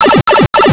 pipewarp.wav